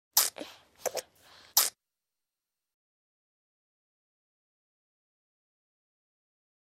Звук облизывания груди ребенком